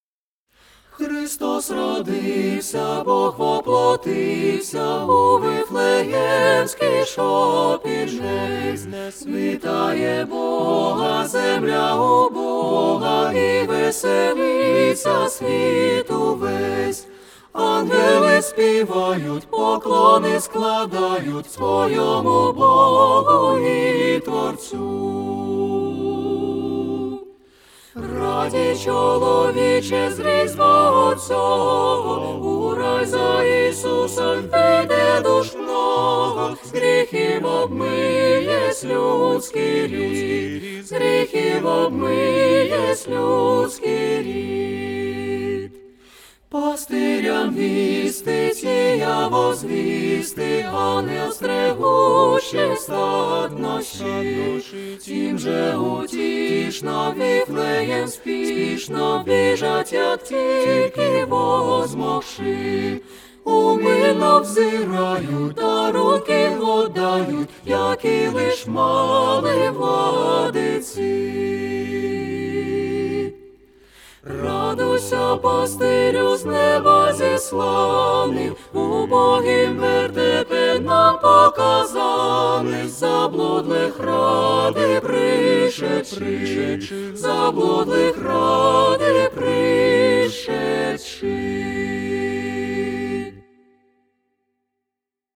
В помощь Самоделкину. "Нерадивый" конденсаторный микрофон зазвучит лучше!
Ансамбль солистов Академического хора НИУ БелГУ - Христос родився_Рождественская колядка.mp3 Ансамбль солистов Академического хора НИУ БелГУ - Христос родився_Рождественская колядка.mp3 3,6 MB · Просмотры: 2.857